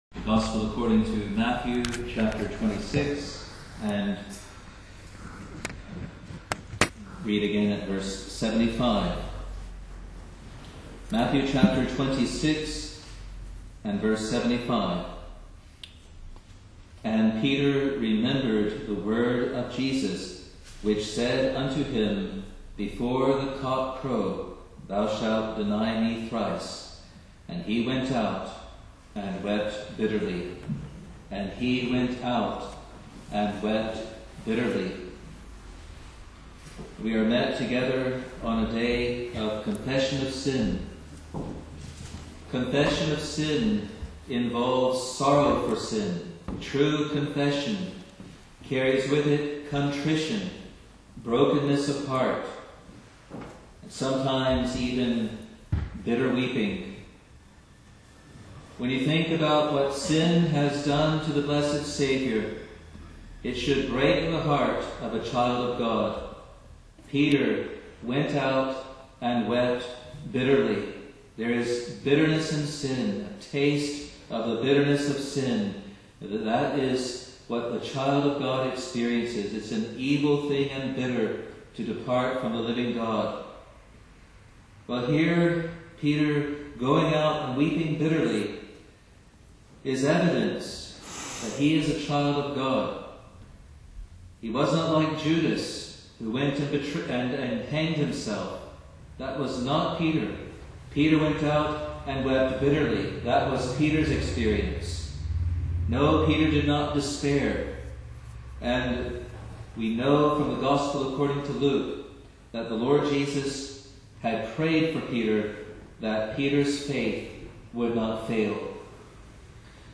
Gisborne Communion April 2015 | Free Presbyterian Church of Scotland in New Zealand